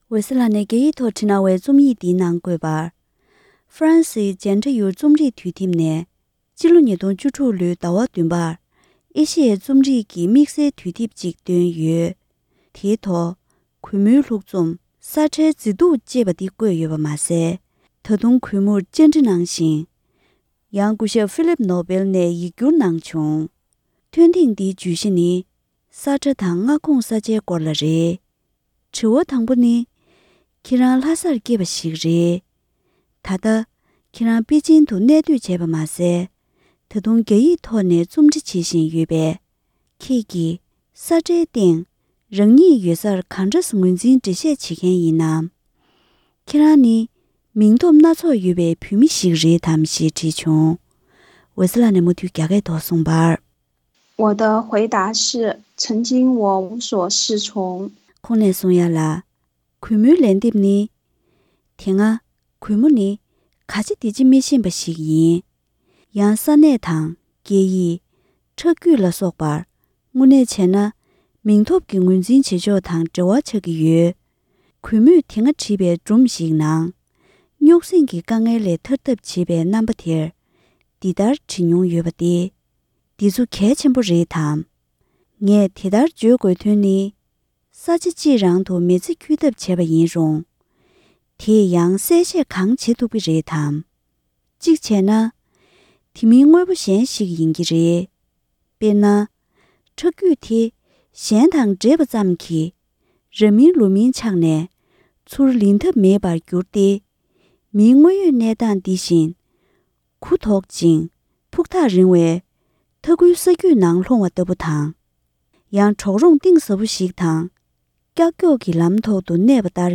ཧྥ་རན་སིའི་Jentayuདུས་དེབ་ནས་འོད་ཟེར་ལགས་སུ་བཅར་འདྲི།